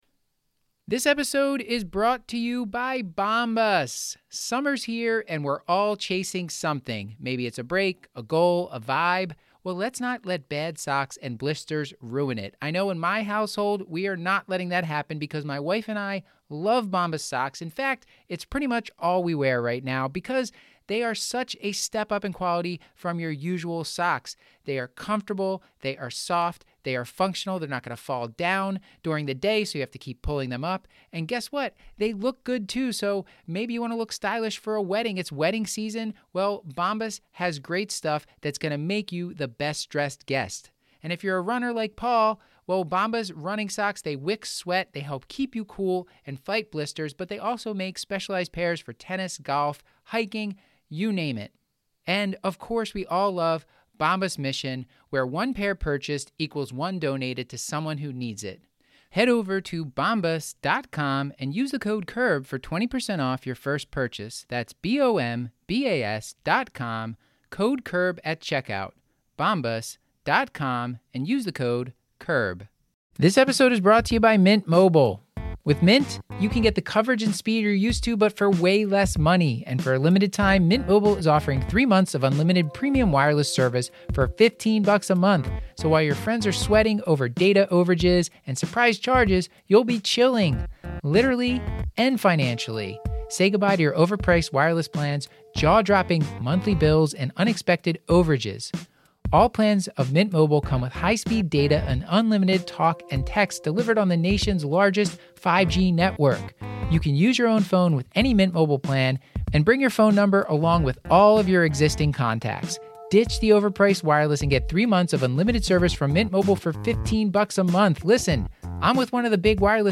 Random clinical pearls from day 2 of ACP 2018 live in New Orleans.